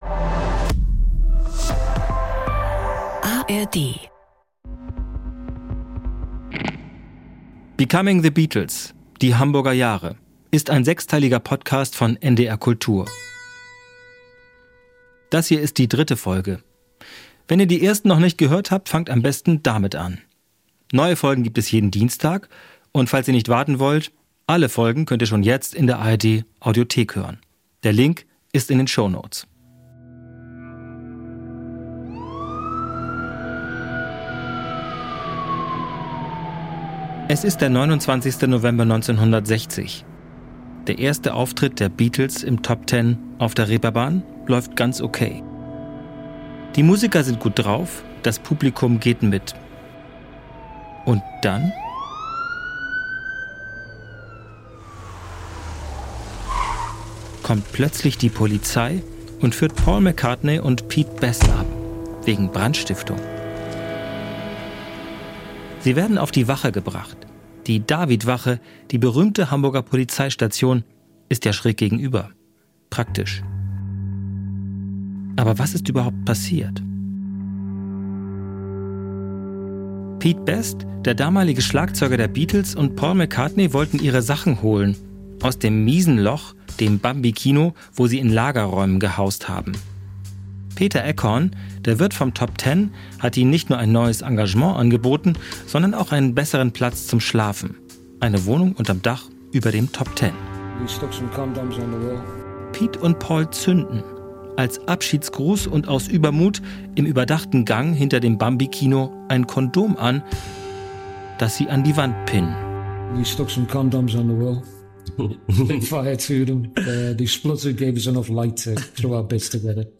Ein Podcast mit viel Musik, O-Tönen von Zeitzeugen und seltenem Archivmaterial.